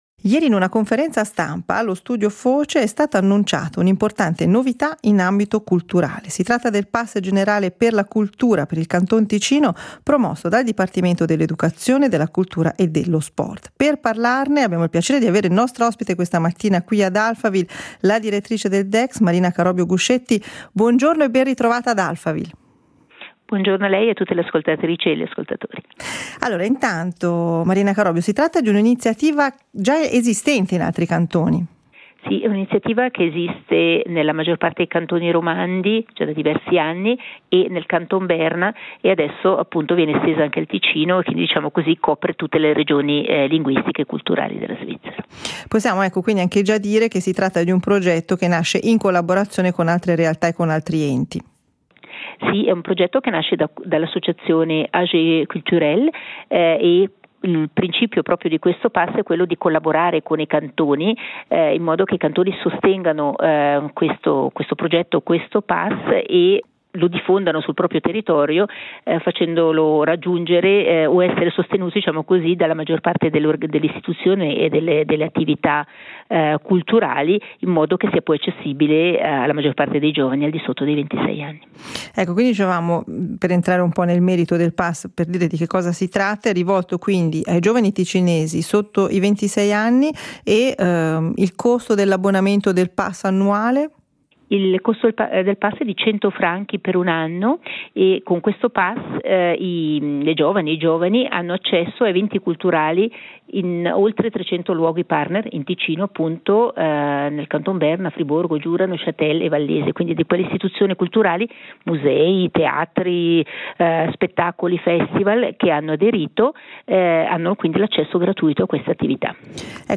Ieri in una conferenza stampa allo Studio Foce è stato annunciato il pass generale per la cultura per il Canton Ticino promosso dal Dipartimento dell’educazione, della cultura e dello sport. Ad Alphaville ne abbiamo parlato con la Consigliera di Stato del Canton Ticino e direttrice del DECS, Marina Carobbio Guscetti.